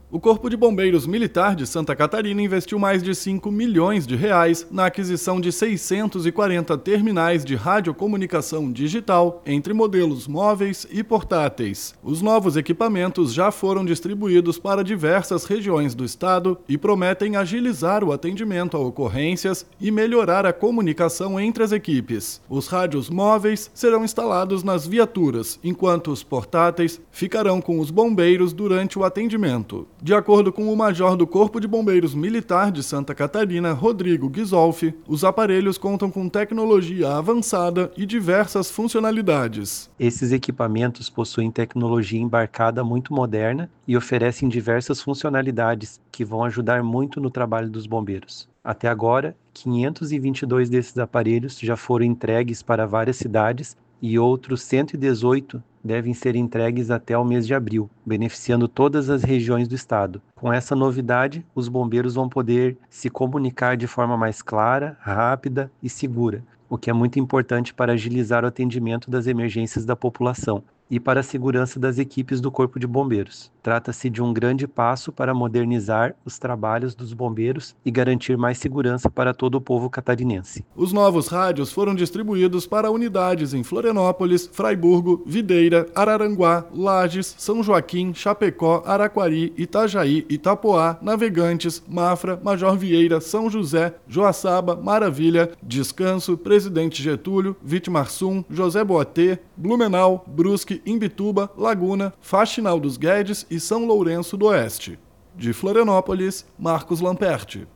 BOLETIM – Corpo de Bombeiros Militar investe em tecnologia de radiocomunicação digital para otimizar atendimento à população